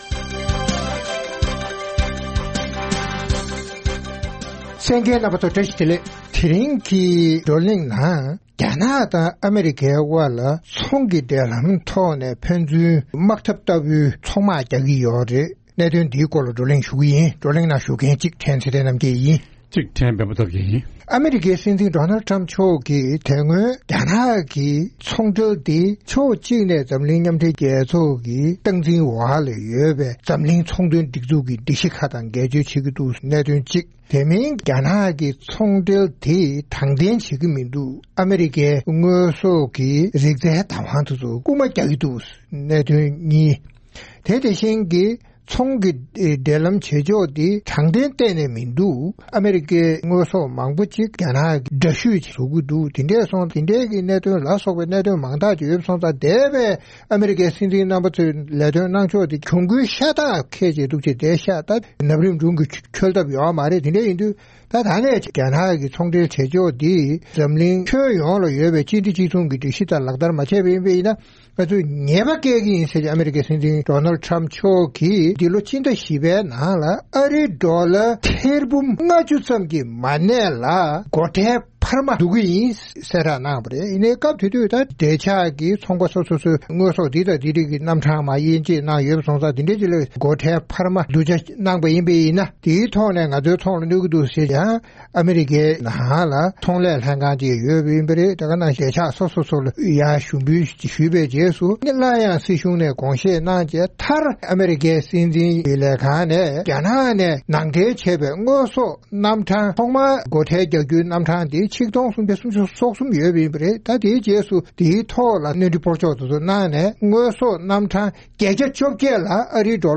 རྩོམ་སྒྲིག་པའི་གླེང་སྟེགས་ཞེས་པའི་ལེ་ཚན་ནང་། ཨ་མེ་རི་ཁ་དང་རྒྱ་ནག་གཉིས་དབར་ཚོང་འབྲེལ་དོ་མཉམ་མེད་པའི་དཀའ་རྙོག་ཡོད་སྟབས། ཕན་ཚུན་དངོས་ཟོག་ནང་འདྲེན་བྱས་པར་སྒོ་ཁྲལ་སྣོན་མ་བསྡུས་དང་བསྡུ་བཞིན་པའི་གནད་དོན་སྐོར་རྩོམ་སྒྲིག་འགན་འཛིན་རྣམ་པས་བགྲོ་གླེང་གནང་བ་ཞིག་གསན་རོགས་གནང་།